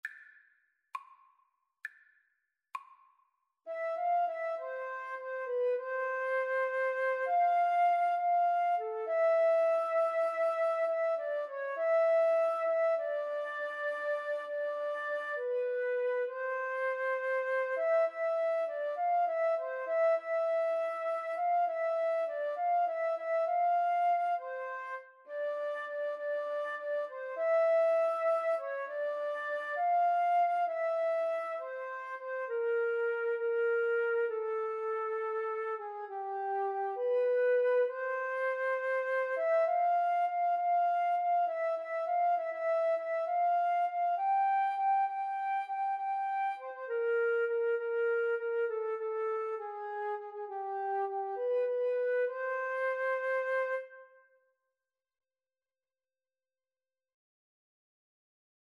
Christian
6/8 (View more 6/8 Music)
Classical (View more Classical Flute Duet Music)